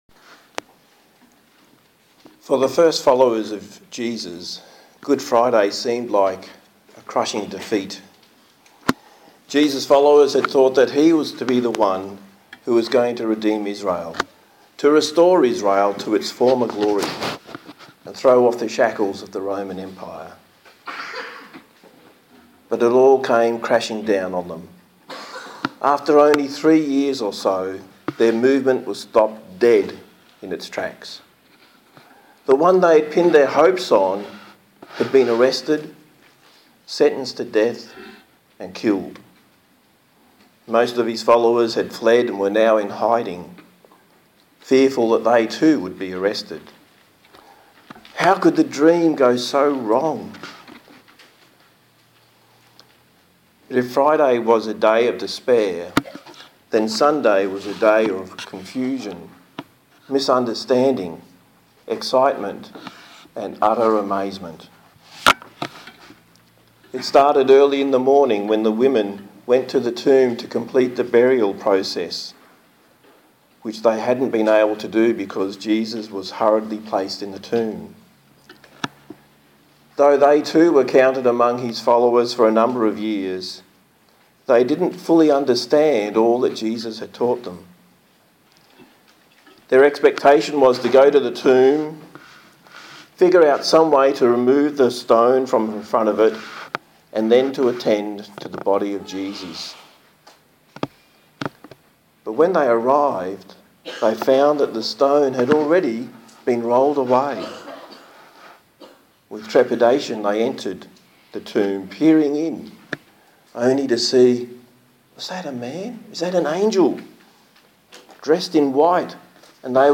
A sermon on Easter from the book of John
Service Type: Sunday Morning